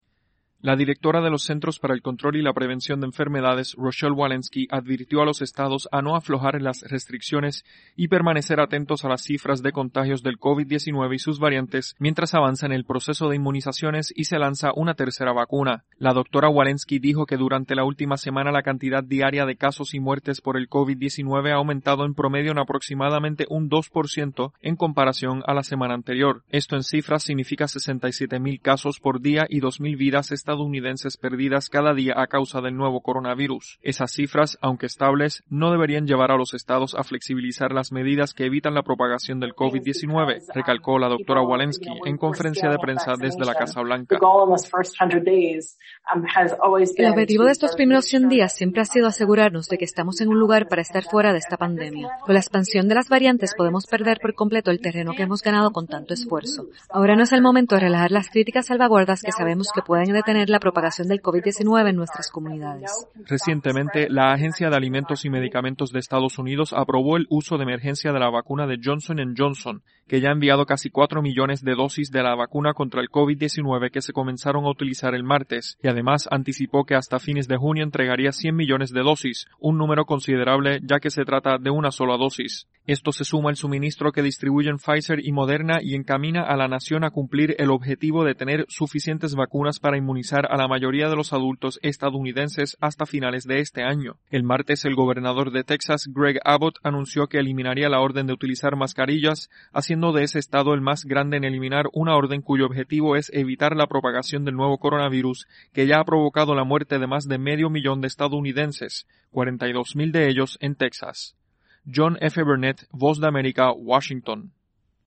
La directora de los CDC urge a los Estados a no bajar la guardia frente al avance del COVID-19 y sus variantes. Informa